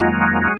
键盘 " 风琴04
描述：记录自DB33Protools Organ。 44khz 16位立体声，无波块。
Tag: 键盘 器官 DB33